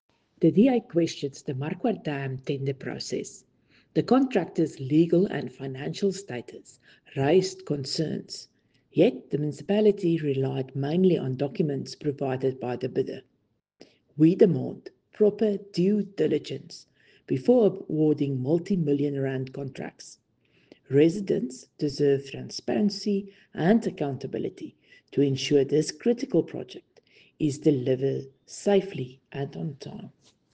Afrikaans soundbites by Cllr Riëtte Dell and